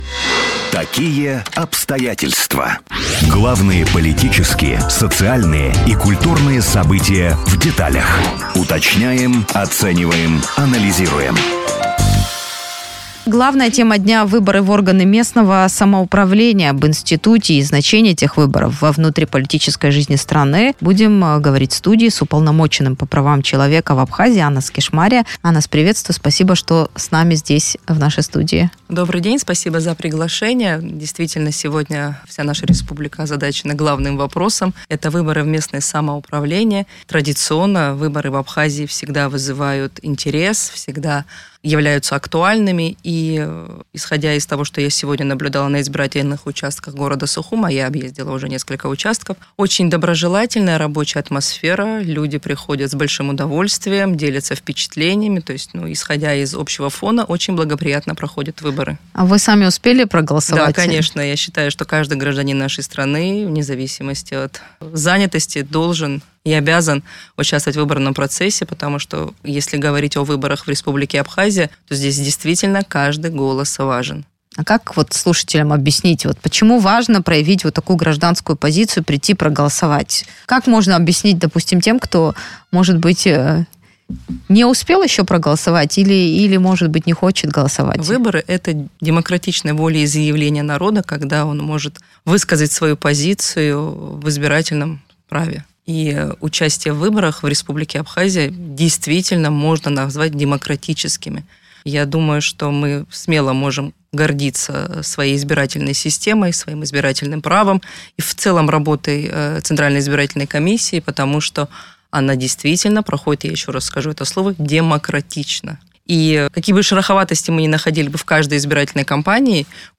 Об институте местных выборов и их значении для внутриполитической жизни страны, в эфире радио Sputnik говорили с Уполномоченным по правам человека в Абхазии Анас Кишмария.